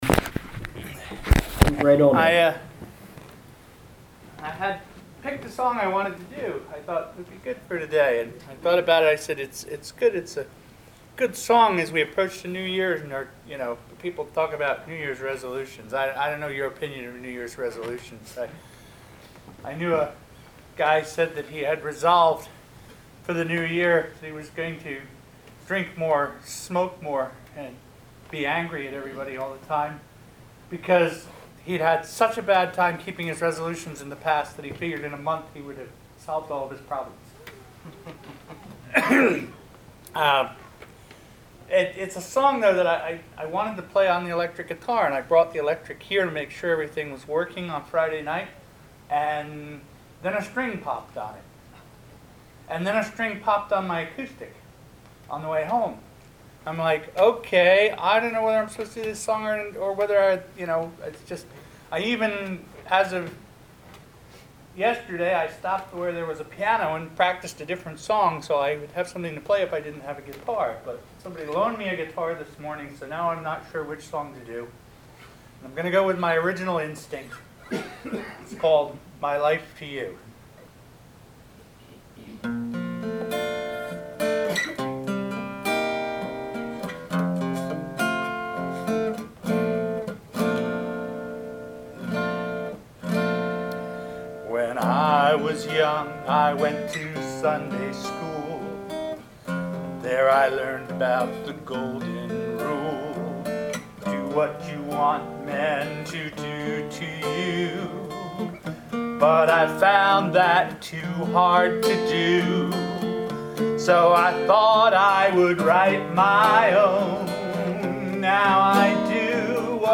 I also went for a mellow jazz flavor.
this live recording from a late December 2014 Sunday morning appearance at the Silverlake Community Church using a borrowed acoustic guitar, complete with introductory comments.